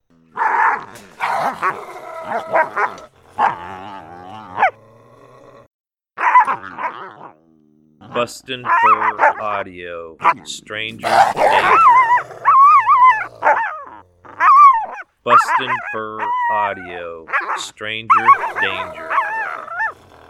Subordinate Juvenile Female Coyote that takes a lashing from a Dominant Male Coyote. Lots of growling, barking and distress in this sound that the wild Coyotes you're calling, will respond to.